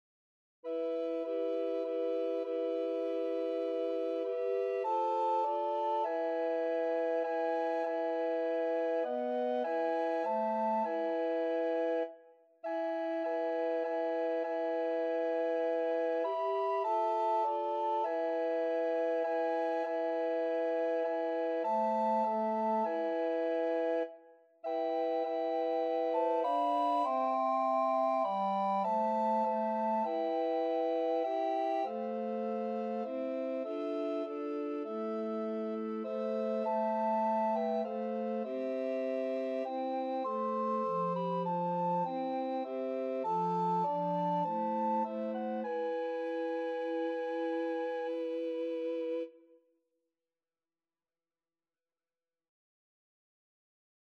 Soprano RecorderAlto RecorderTenor RecorderBass Recorder
4/4 (View more 4/4 Music)
Classical (View more Classical Recorder Quartet Music)